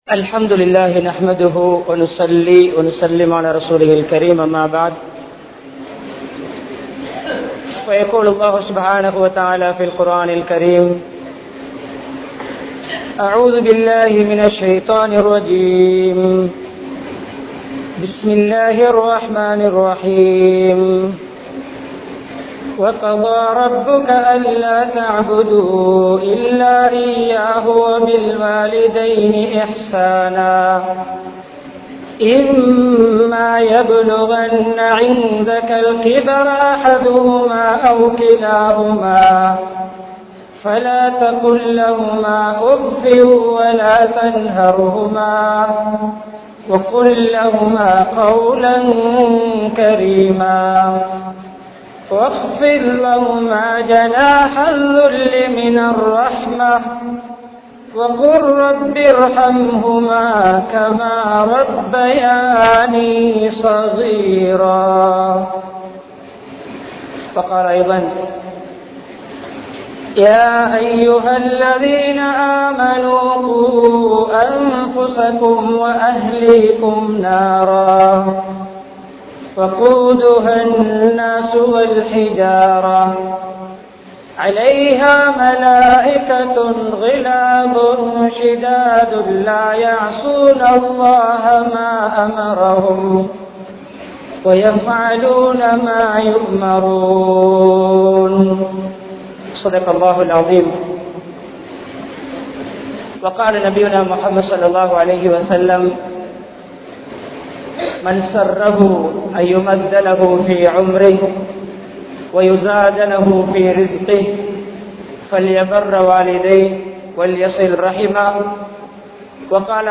Pettroarhalai Avamathikkaatheerhal (பெற்றோர்களை அவமதிக்காதீர்கள்) | Audio Bayans | All Ceylon Muslim Youth Community | Addalaichenai
Eravur, Meerakeni Jumua Masjidh